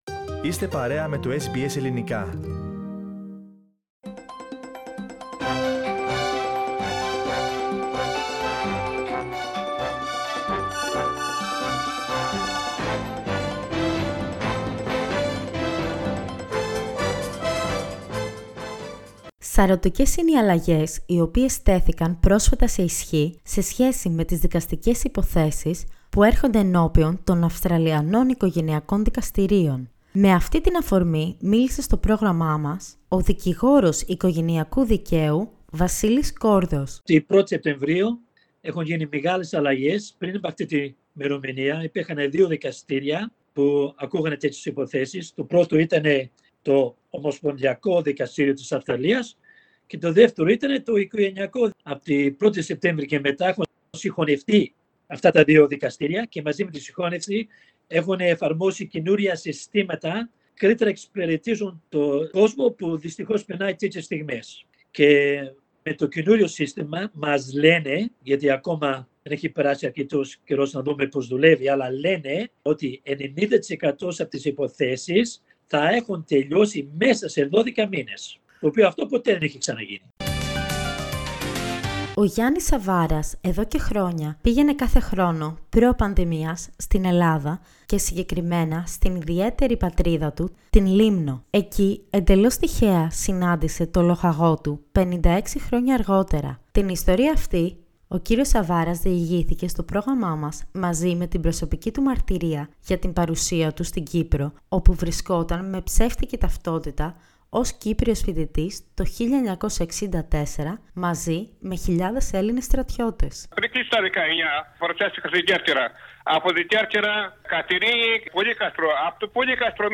O πολύπειρος δικηγόρος οικογενιεακού δικαίου που μιλά για τα Αυστραλιανά οικογενειακά δικαστήρια, ο ομογενής που μας διηγείται την προσωπική του ιστορία όταν ήταν στην Κύπρο, ο νομικός που αναλύει την σύναψη Σύμβασης Διπλής Φορολογίας, η φωτογράφος που συλλέγει χρήματα για την κουζίνα μίας ενορίας, ήταν μερικά από τα πρόσωπα που μας μίλησαν την περασμένη εβδομάδα.